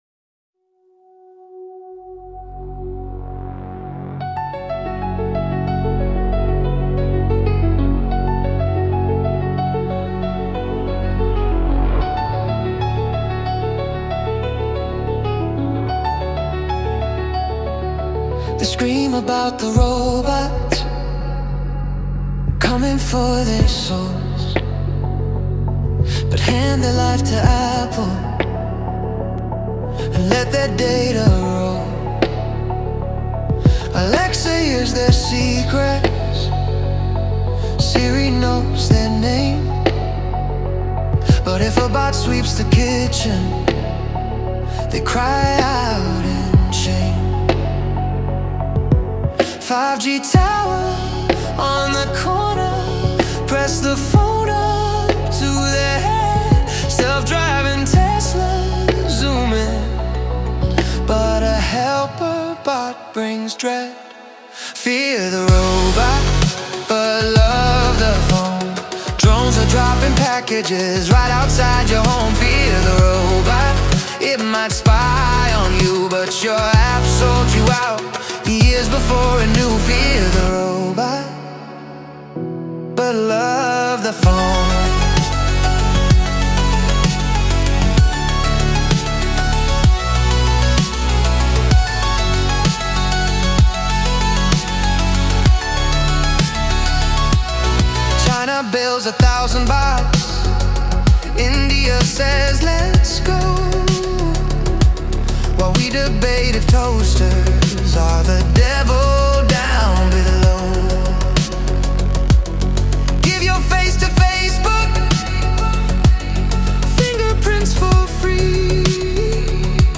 Musical poetry
Most vocals are AI generated.
Tagged Under Hip-Hop Music Videos Musical Poetry Pop Rap